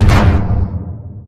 combat / aircraft / boomout.ogg
boomout.ogg